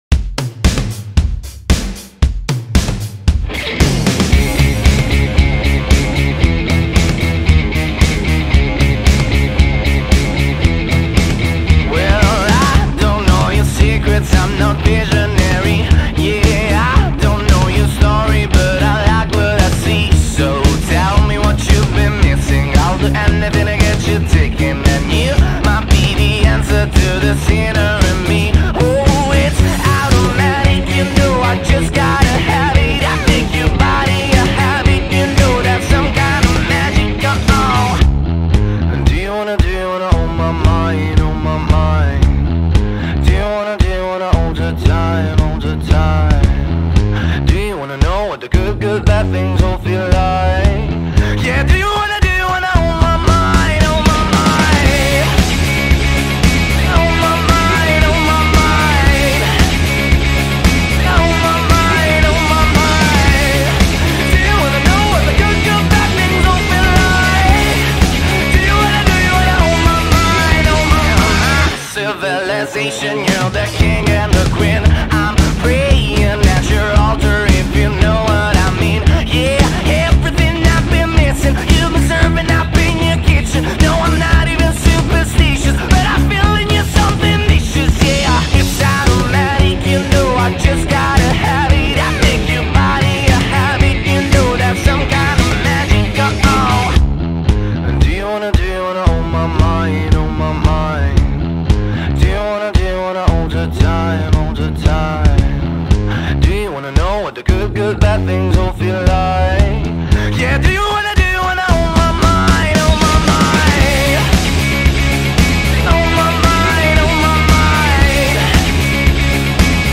Alternative Rock, Pop Rock